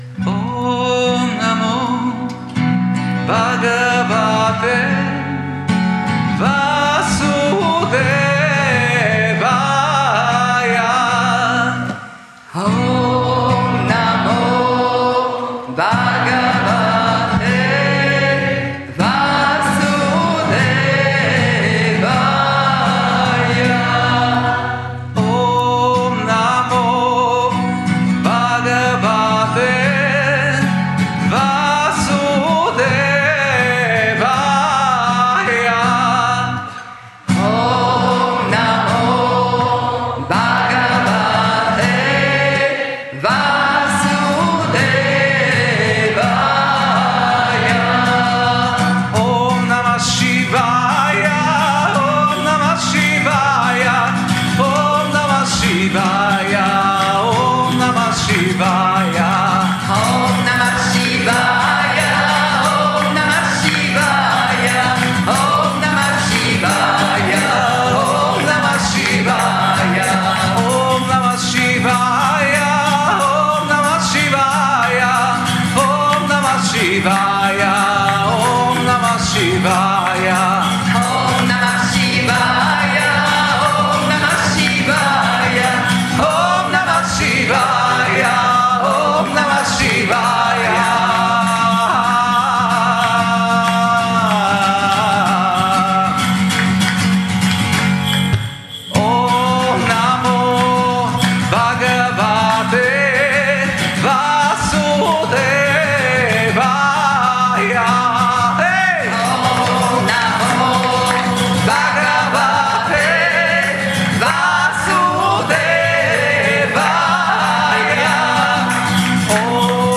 Om Namo Bhagavate Vasudevaya Mantra mit Gruppe Mudita 6:37
Om Namo Bhagavate Vasudevaya Lausche dem Om Namo Bhagavate Vasudevaya gesungen von Gruppe Mudita während eines Samstagabend Satsangs bei Yoga Vidya in Bad Meinberg.